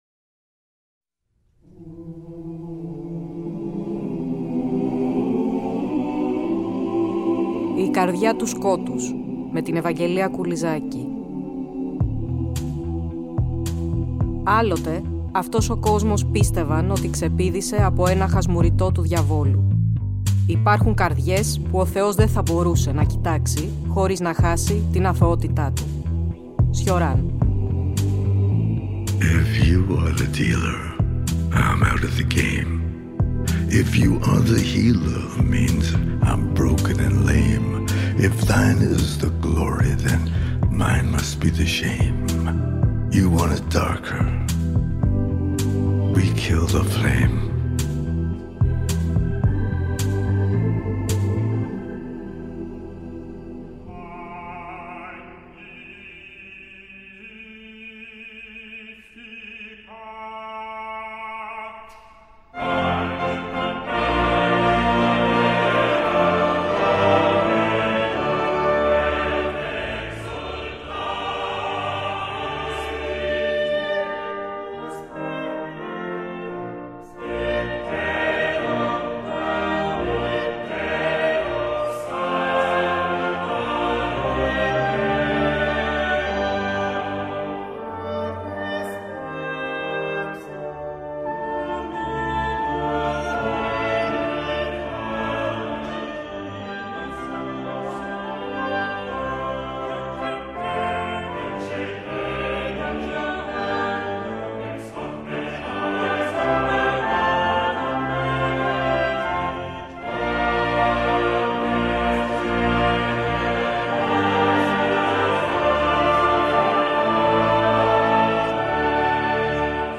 Πλαισιώνουμε μουσικά (και) με αποσπάσματα από το έργο “Faust” του Charles Gounod.